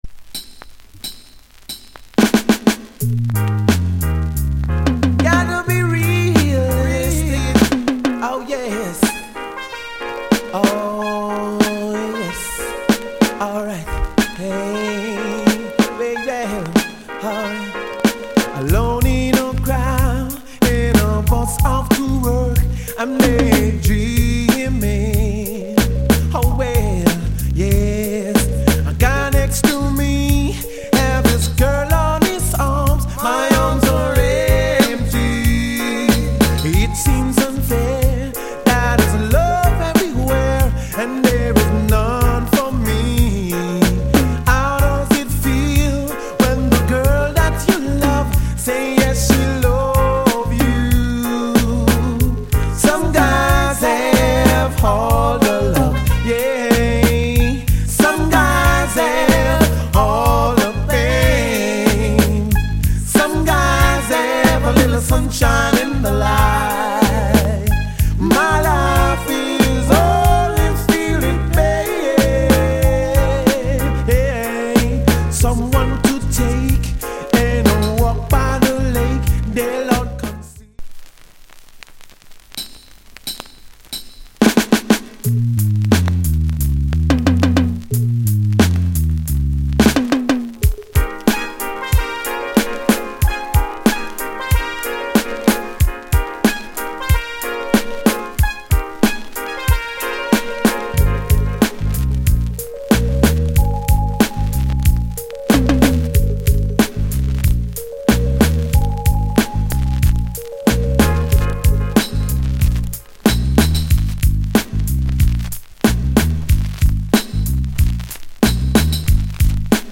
Genre Reggae80sLate / Male Vocal